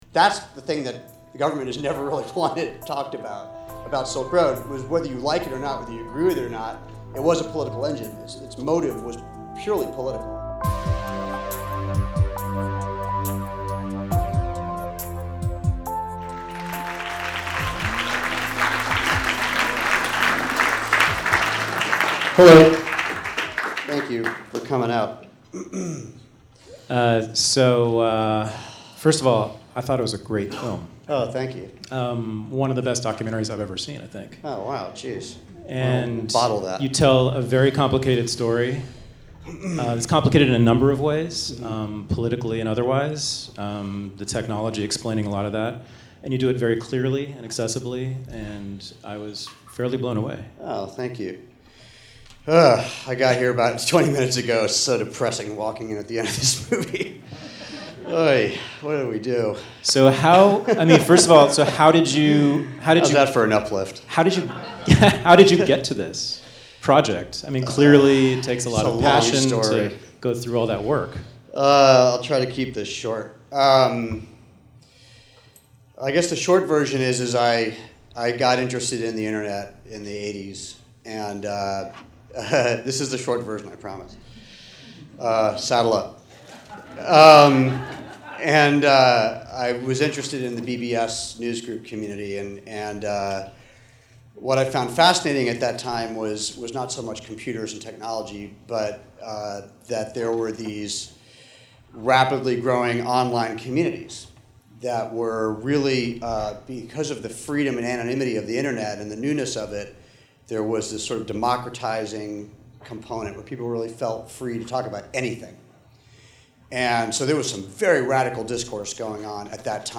Q&A with Deep Web director Alex Winter.